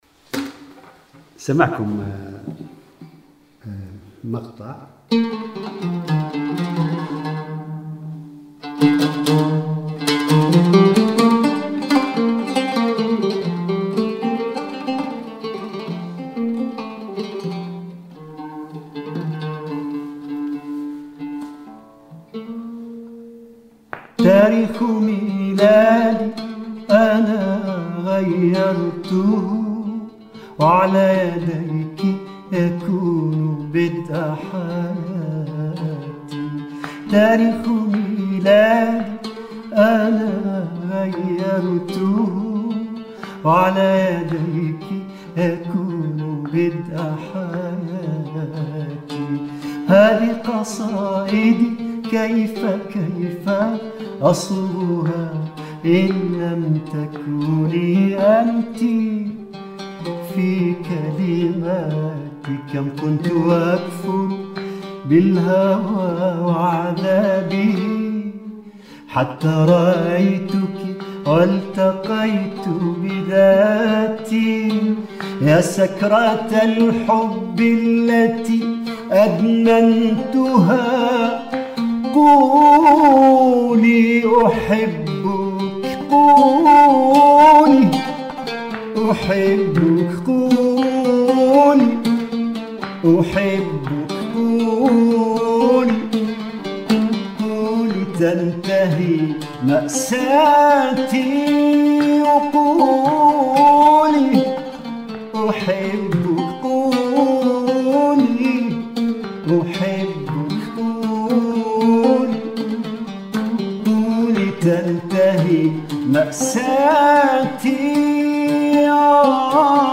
على العود